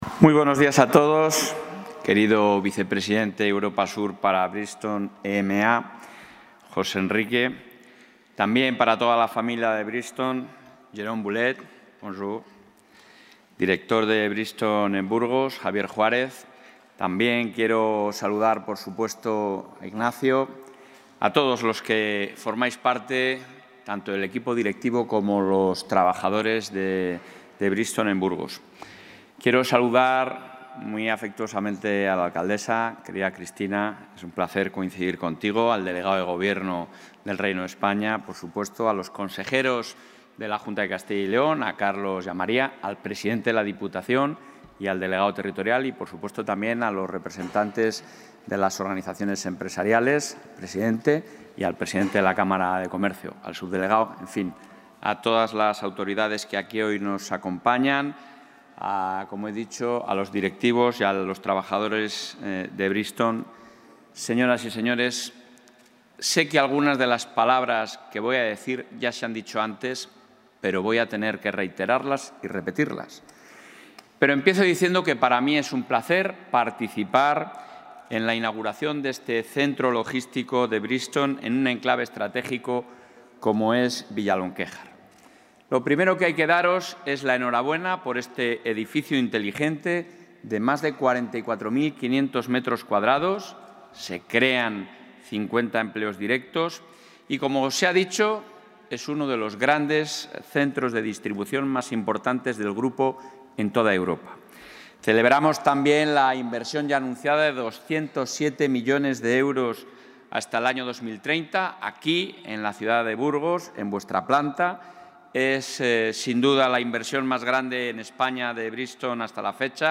Intervención del presidente de la Junta.
El presidente de la Junta de Castilla y León, Alfonso Fernández Mañueco, ha inaugurado hoy el nuevo centro logístico de Bridgestone en el Polígono Industrial de Villalonquéjar, en Burgos, uno de los más importantes que tiene este grupo en Europa y que crea 50 empleos directos.